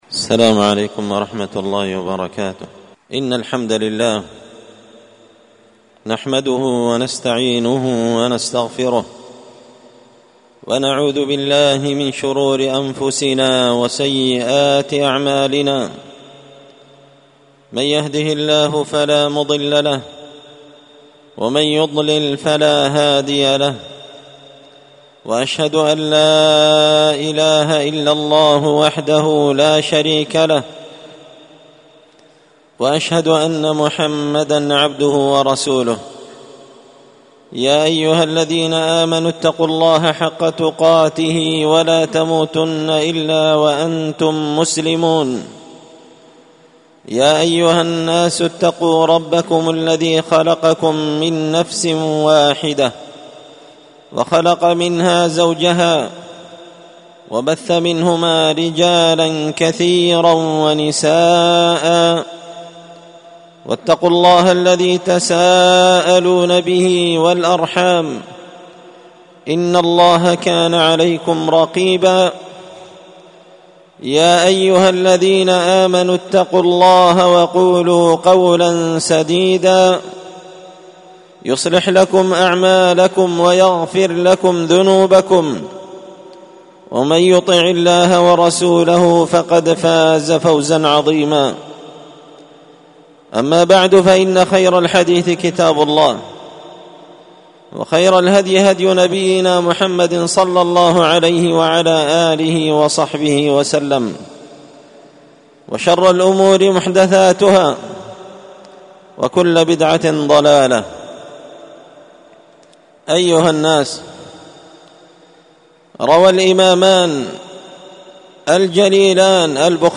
خطبة جمعة بعنوان: